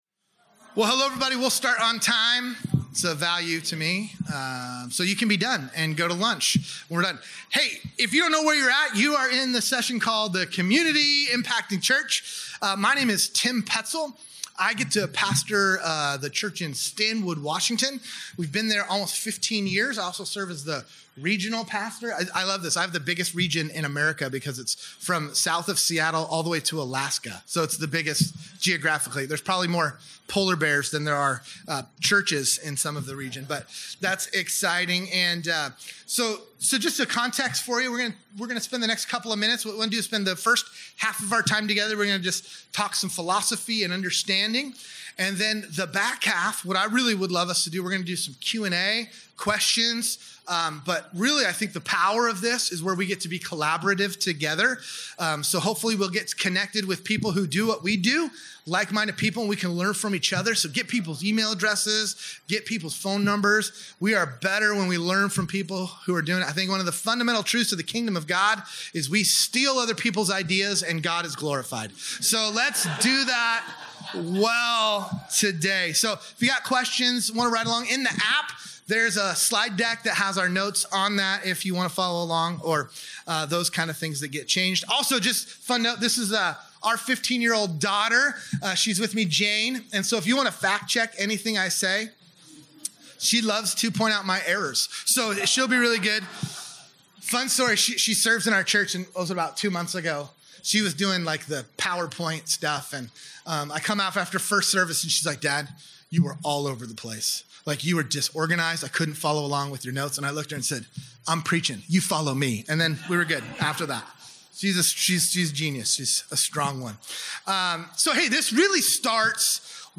This workshop provides clear steps for how your local church can be practically involved in your community while sharing the message of Jesus.